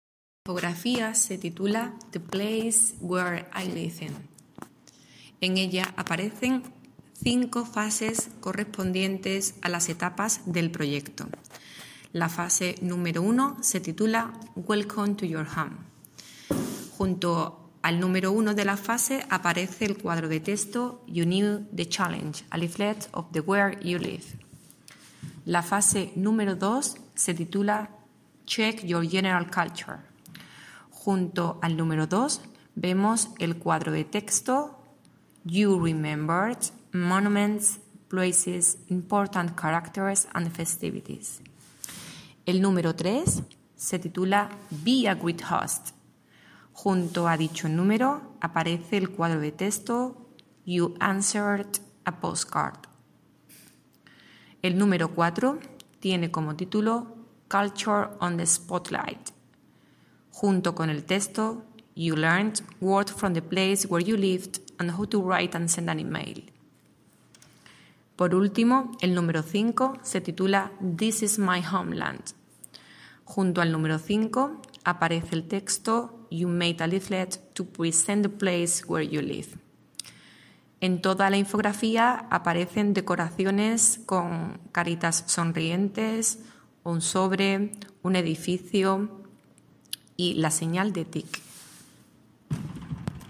AUD_ING5PRI_REA7_FASE6_AUDIODESCRIPCION_INFOGRAFIA.mp3